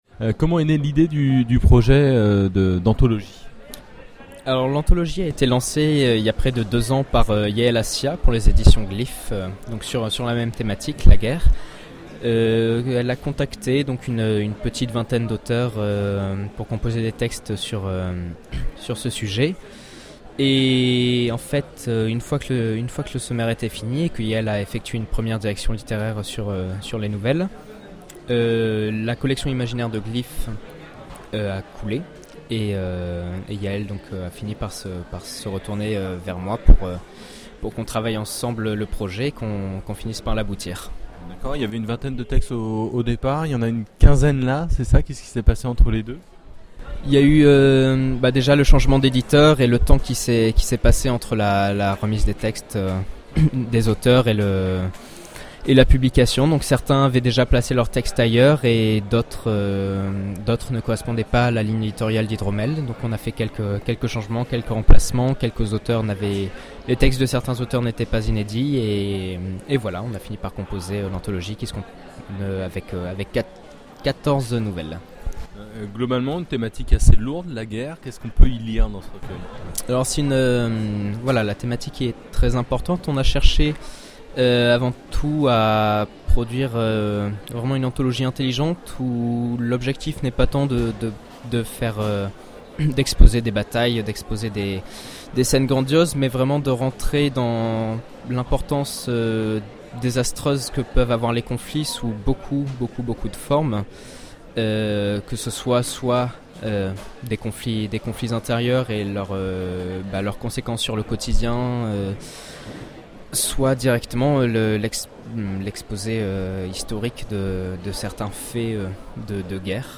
Voici l'enregistrement de l'interview